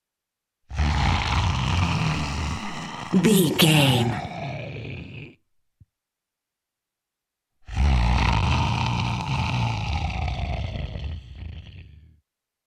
Monster snarl medium creature x2
Sound Effects
scary
dark
eerie
angry